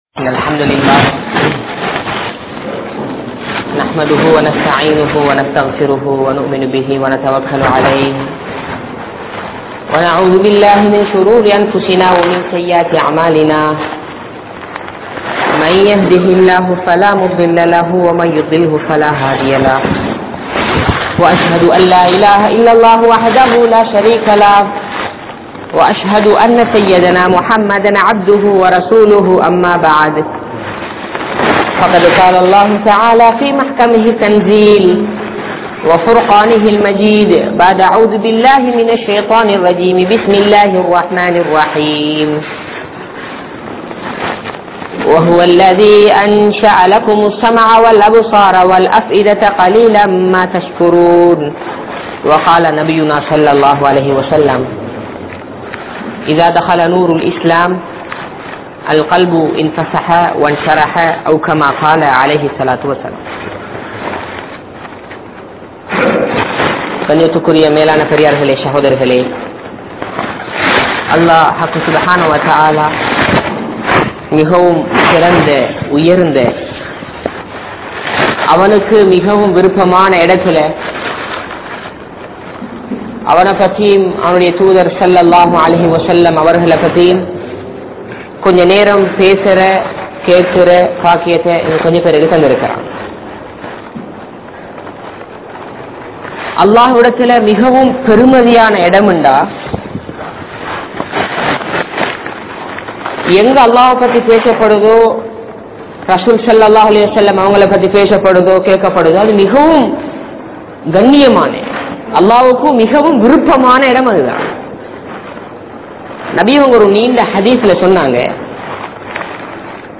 Maranikka Piranthavarhal (மரணிக்க பிறந்தவர்கள்) | Audio Bayans | All Ceylon Muslim Youth Community | Addalaichenai
Grand Jumua Masjidh(Markaz)